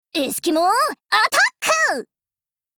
[[Category:碧蓝航线:爱斯基摩人语音]][[Category:碧蓝航线:爱斯基摩人语音]]
Cv-20132_battlewarcry.mp3